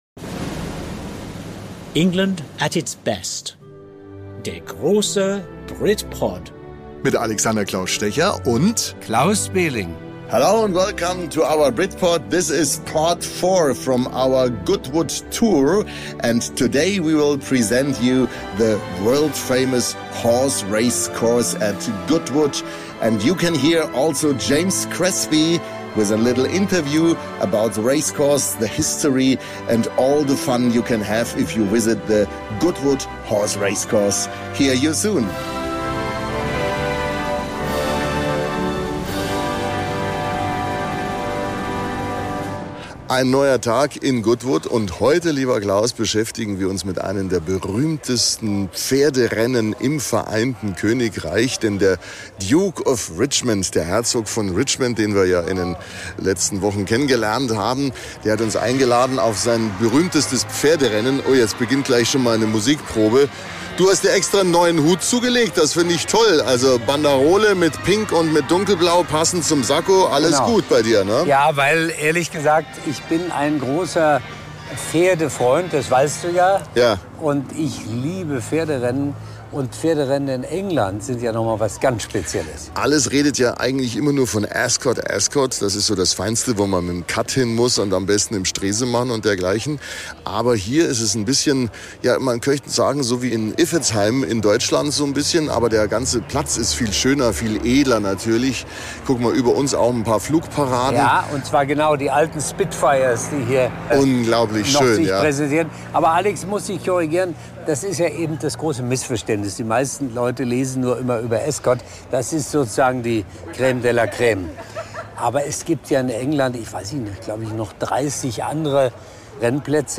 -- An ALL EARS ON YOU Original Podcast.